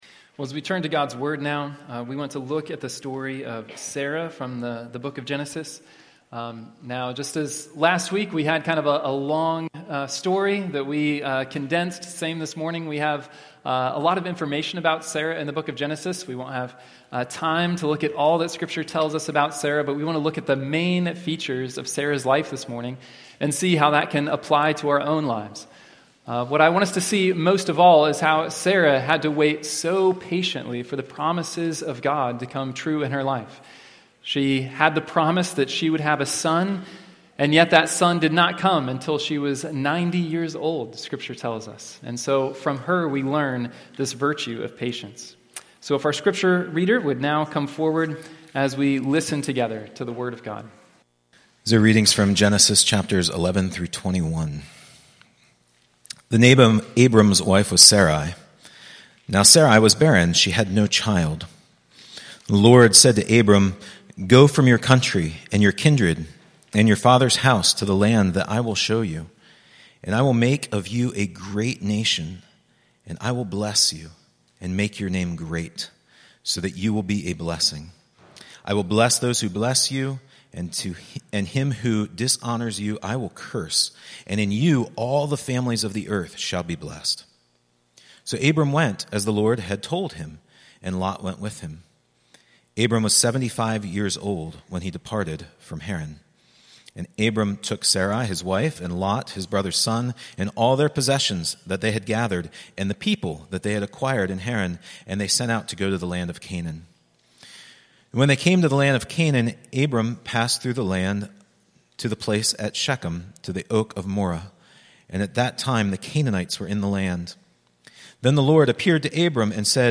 Weekly preaching from Providence Church (Pittsburgh, PA)